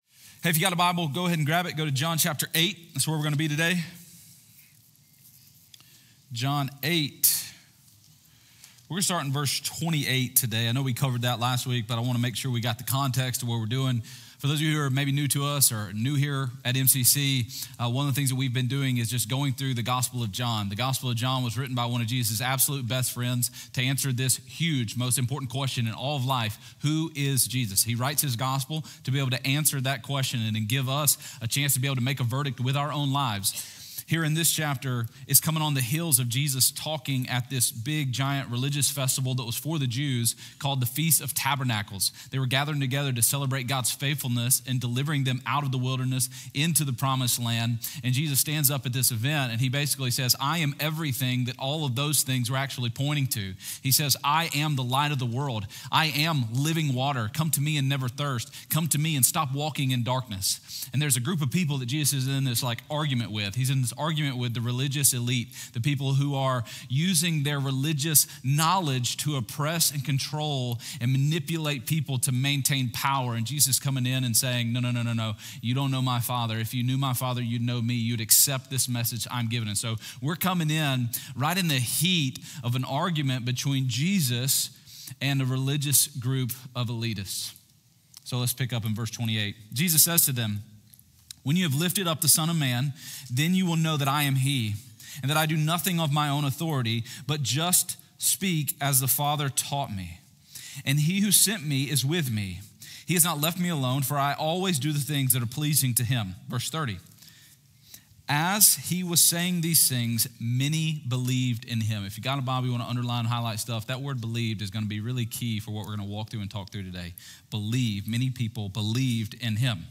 Sermon-101225.m4a